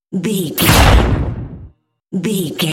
Dramatic hit thunder
Sound Effects
Atonal
heavy
intense
dark
aggressive